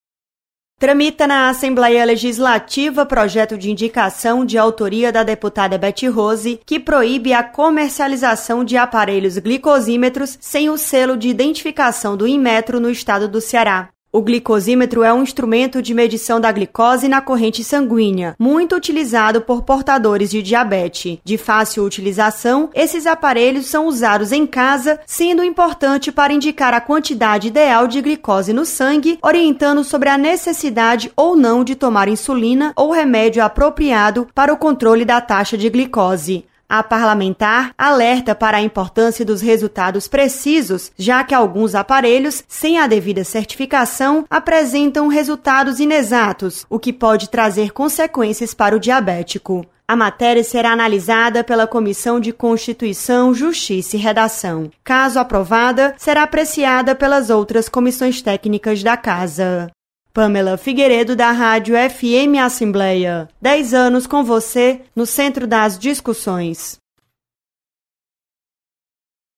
Projeto requer selo do Inmetro para aparelhos de medição de glicose. Repórter